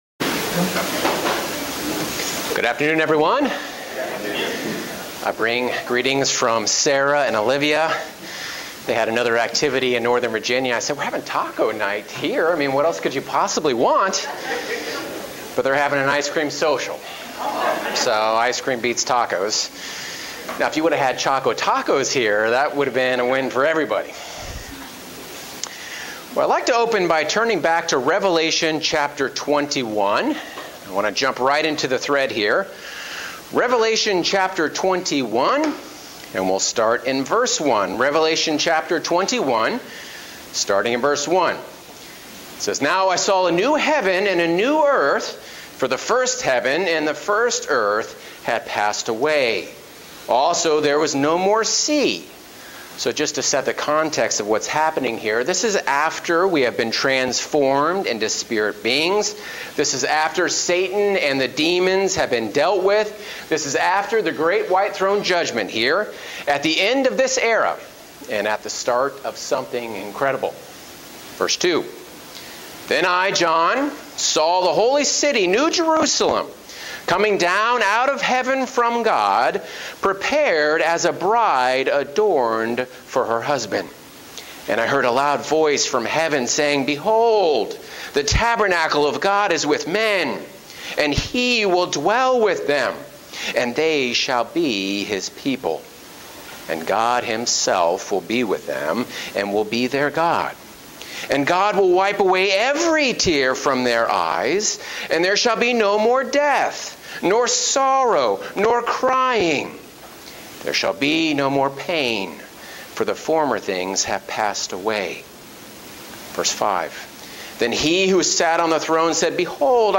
Given in Columbia, MD